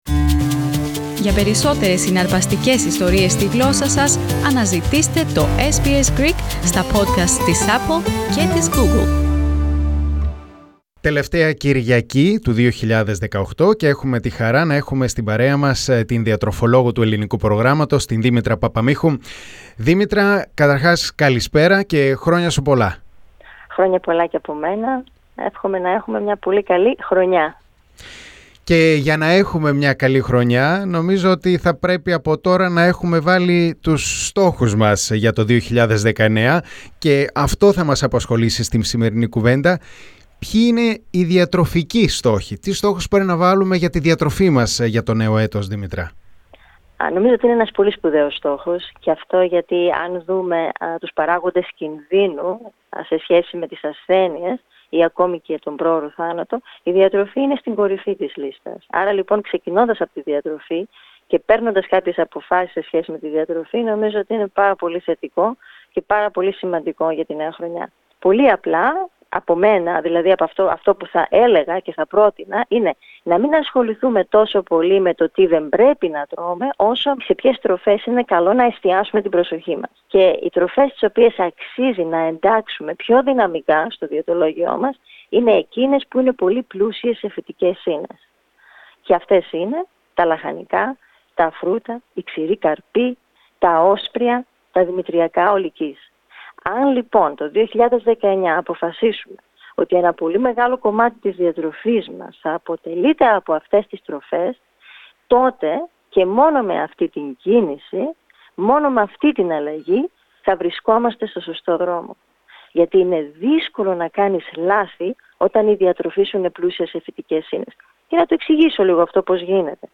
Πατήστε Play στην κεντρική φωτογραφία για να ακούσετε τη συνέντευξη